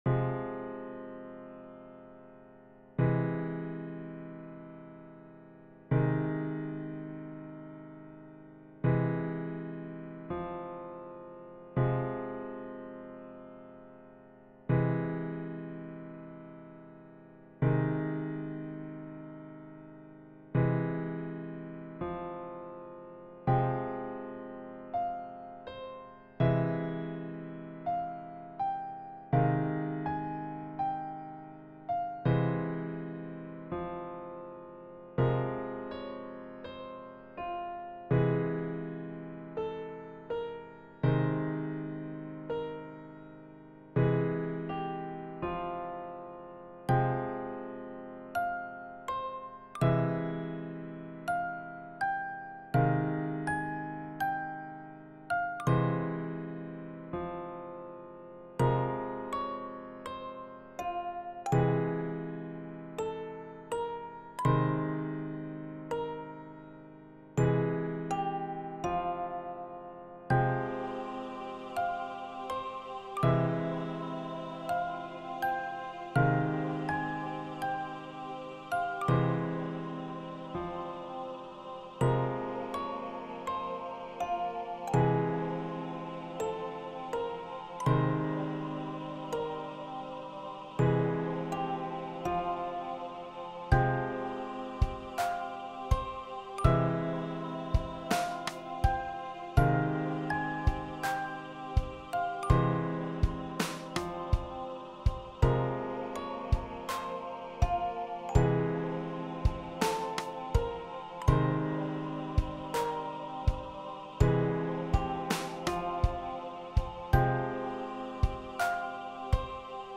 Small sad music made a long time ago for a game, never used it lol
Sad Music